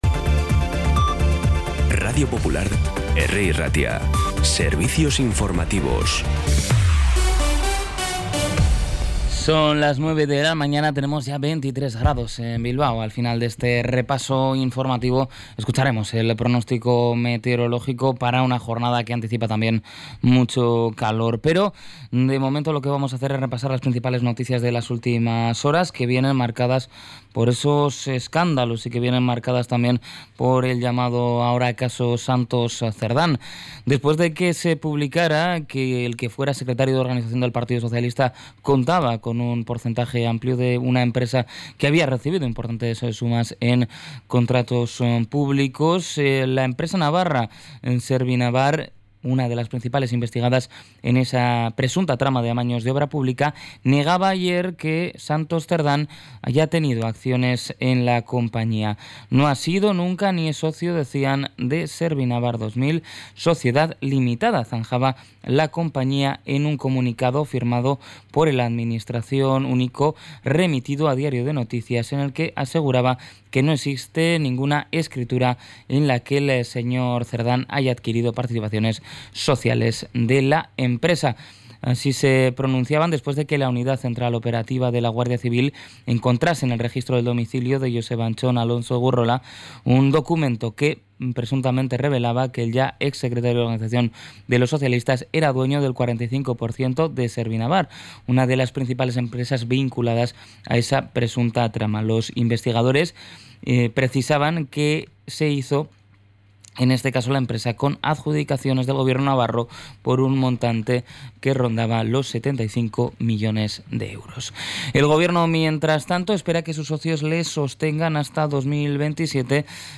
Las noticias de Bilbao y Bizkaia del 20 de junio a las 9
Los titulares actualizados con las voces del día. Bilbao, Bizkaia, comarcas, política, sociedad, cultura, sucesos, información de servicio público.